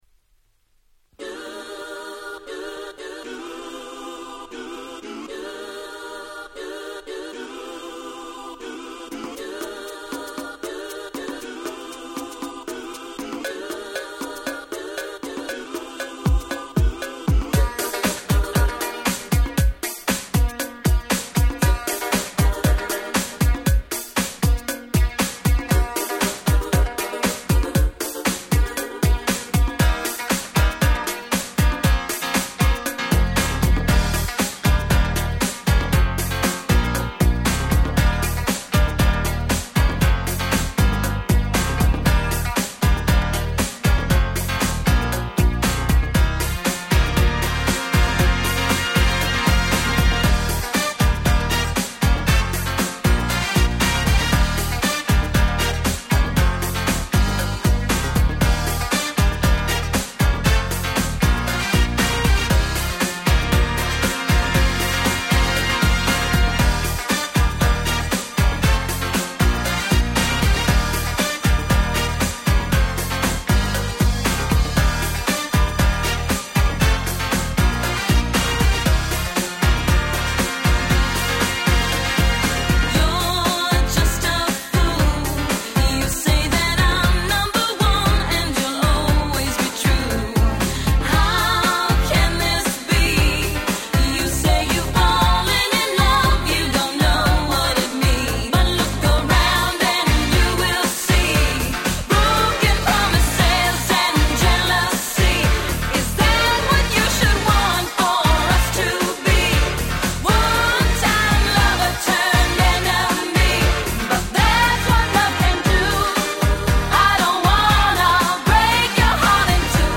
(R'n'B Mix)